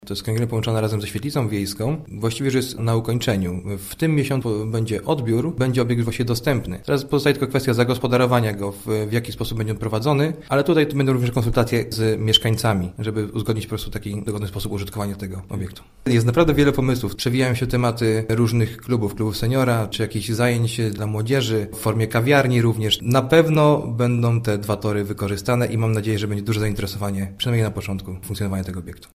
– Wkrótce kręgielnia będzie także w Dychowie, więc nie trzeba będzie pokonywać 10 kilometrów, aby uprawiać bowling – mówi Wojciech Wąchała, wójt gminy Bobrowice.